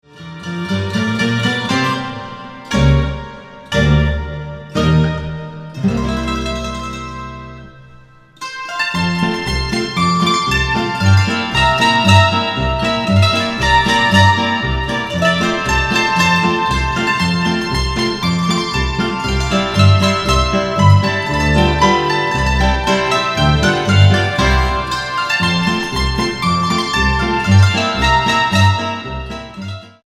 guitarra
contrabajo